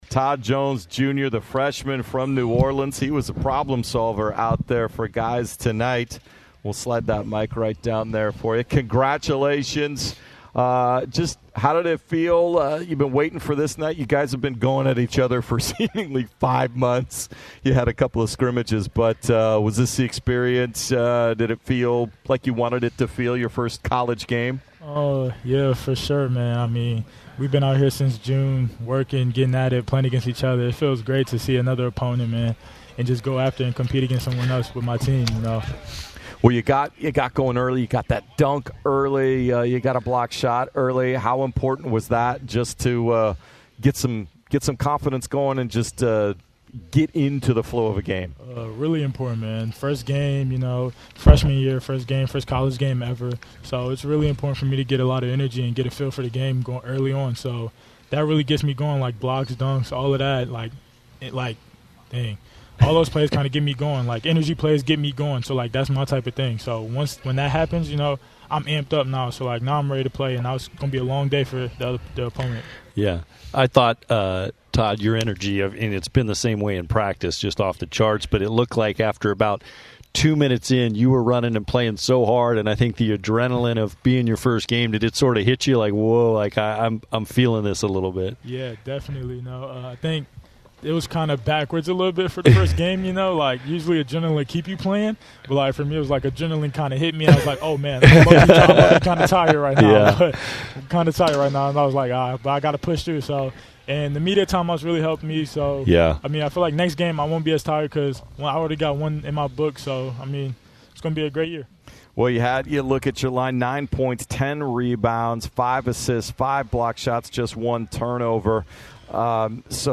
Post-Game Interview vs. Lewis & Clark